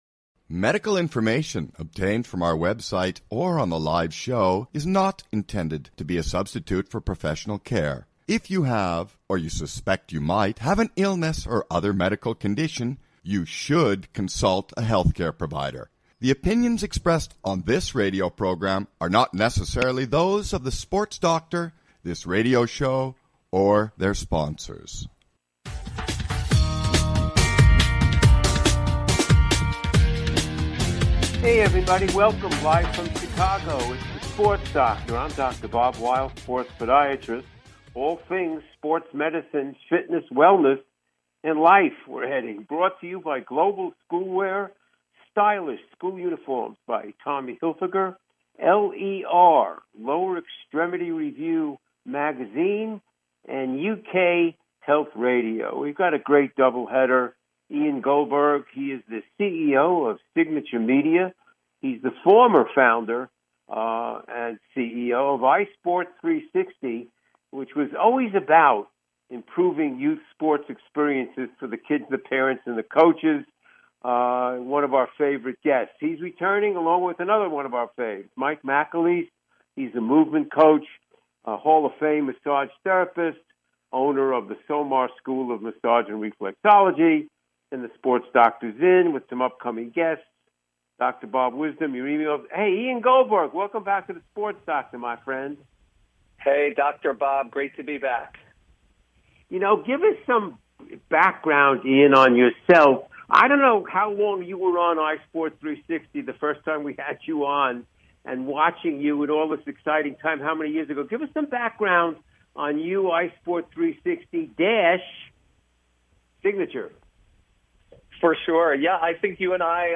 Talk Show Episode, Audio Podcast, The Sports Doctor and Guests